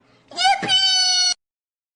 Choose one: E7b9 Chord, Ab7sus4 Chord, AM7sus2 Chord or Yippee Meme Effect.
Yippee Meme Effect